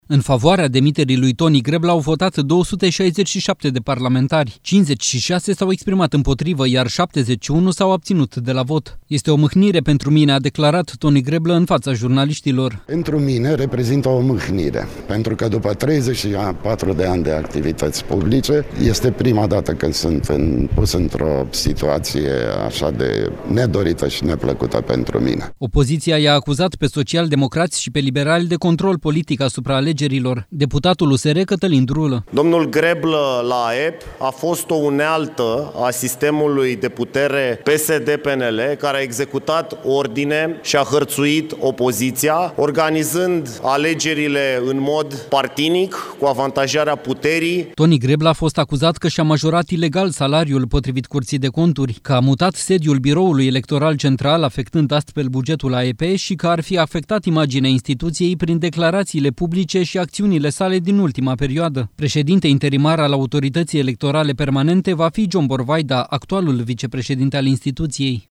„Este o mâhnire pentru mine”, a declarat Toni Greblă în fața jurnaliștilor